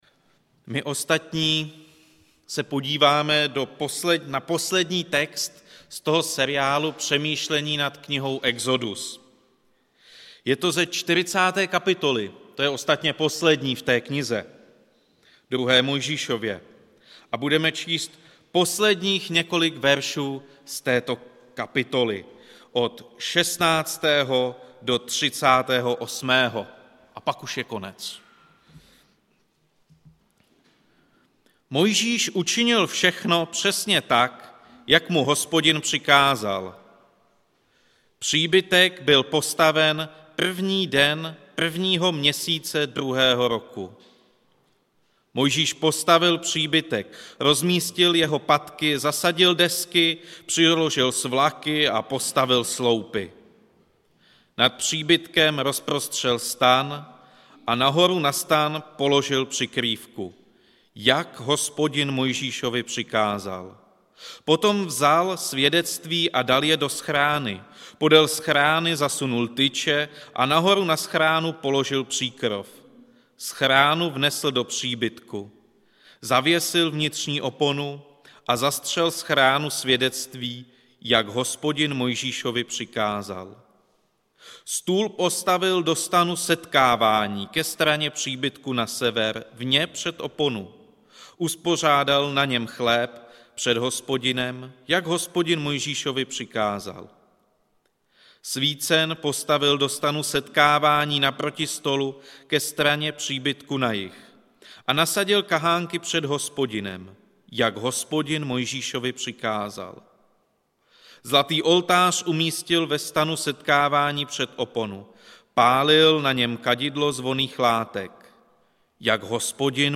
Událost: Kázání
Místo: Římská 43, Praha 2